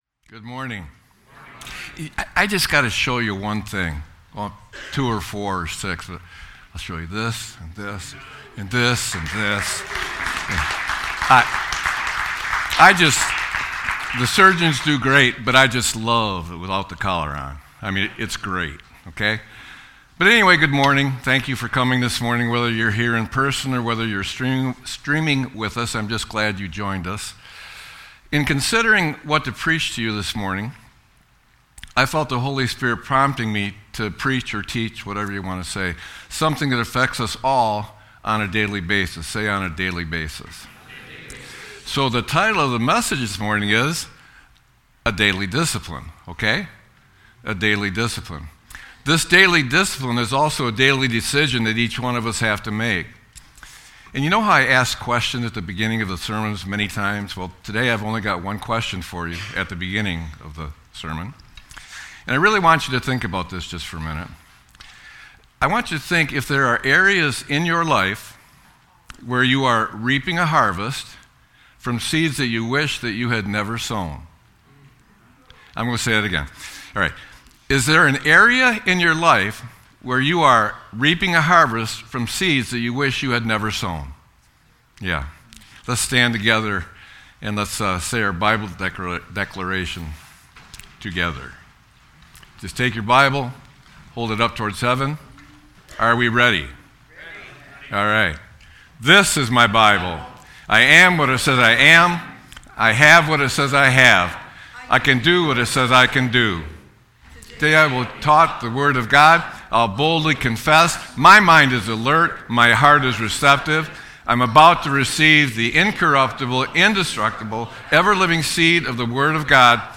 Sermon-1-04-26.mp3